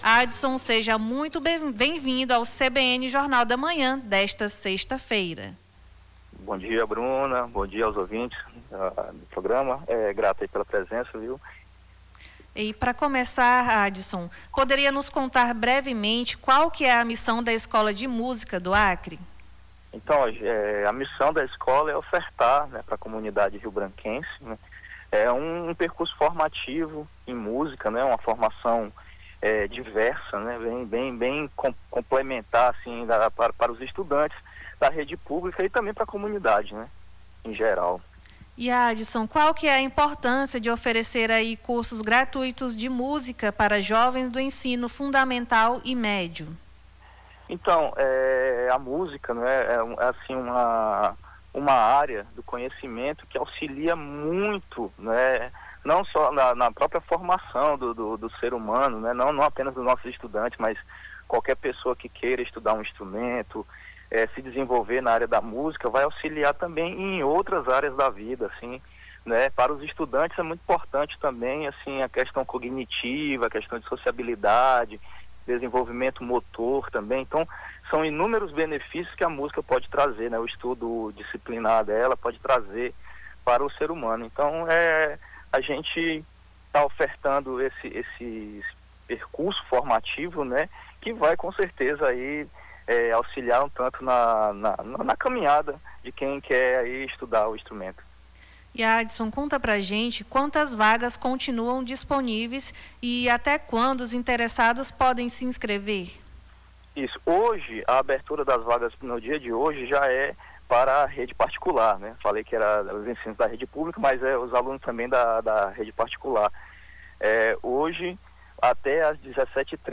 Nome do Artista - CENSURA - ENTREVISTA ESCOLA DE MUSICA DO ACRE - 20-02-26.mp3